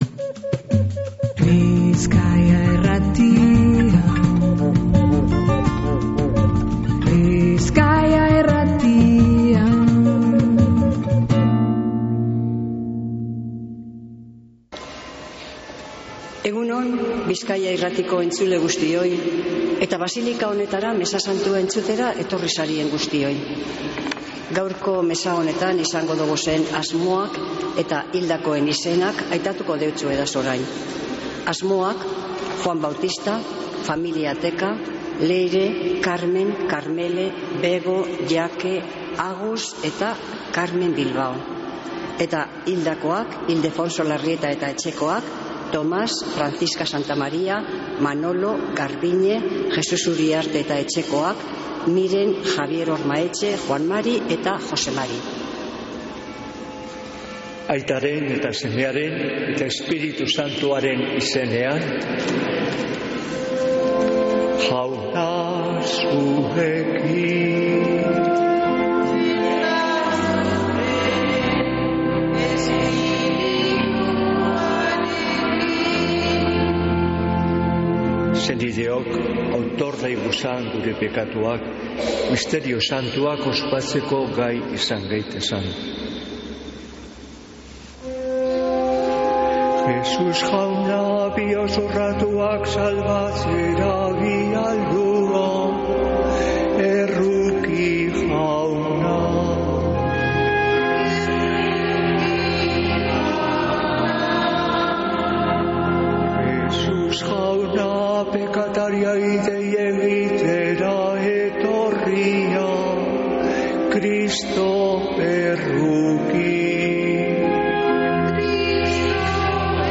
Mezea